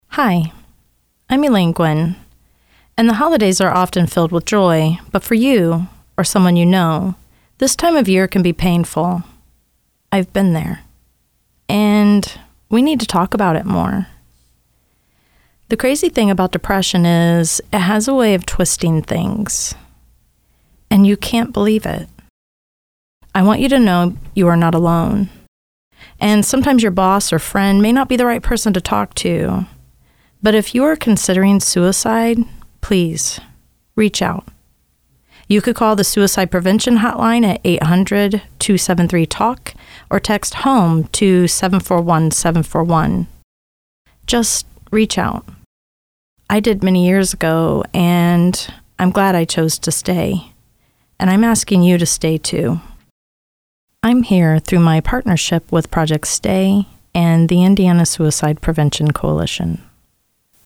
Radio Public Service Annoucements